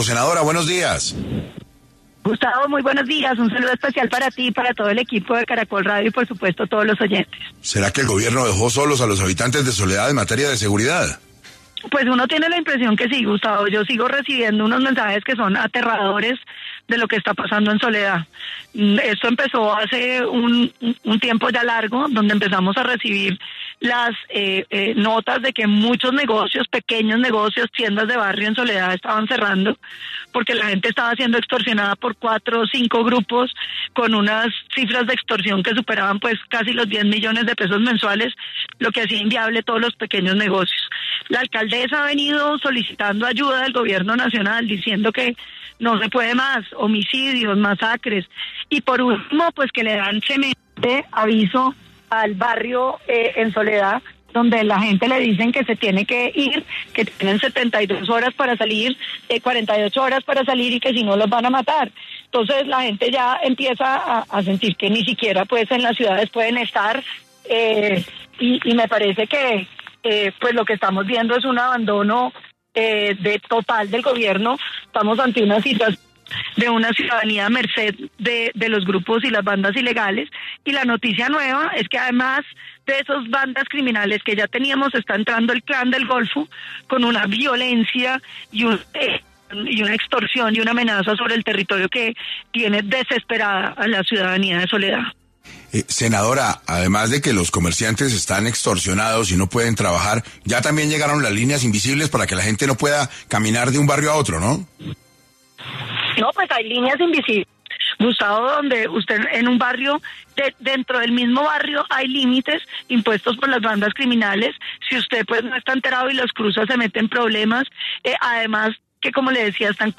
En 6AM de Caracol Radio estuvo Paloma Valencia, quien habló sobre el Gobierno dejó solos a los habitantes de Soledad en medio de ola de inseguridad y el nombramiento de Daniel Mendoza como embajador de Tailandia.